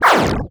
SCIFI_Sweep_02_mono.wav